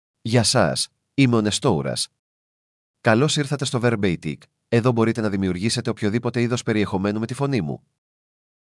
Nestoras — Male Greek (Greece) AI Voice | TTS, Voice Cloning & Video | Verbatik AI
NestorasMale Greek AI voice
Voice sample
Listen to Nestoras's male Greek voice.
Male
Nestoras delivers clear pronunciation with authentic Greece Greek intonation, making your content sound professionally produced.